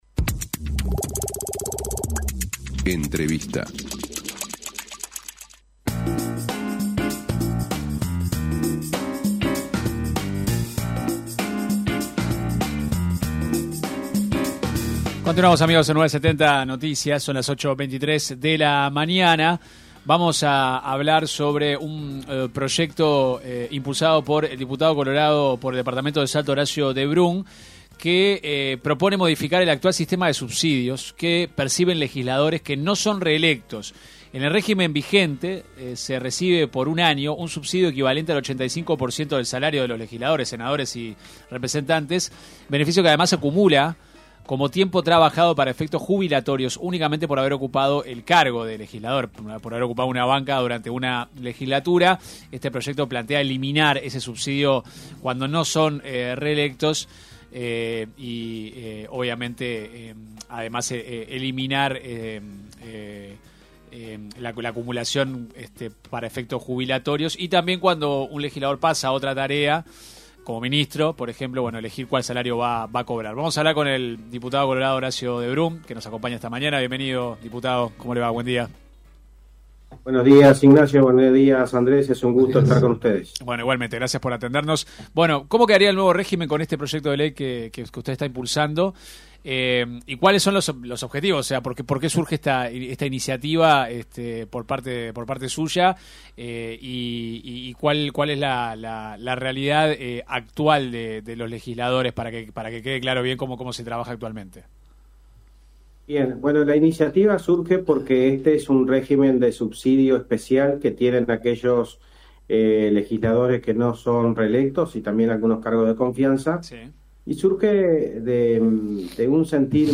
En una entrevista con 970 Noticias , el diputado explicó por qué plantea esto.